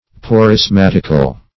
Search Result for " porismatical" : The Collaborative International Dictionary of English v.0.48: Porismatic \Po`ris*mat"ic\, Porismatical \Po`ris*mat"ic*al\, a. Of or pertaining to a porism; poristic.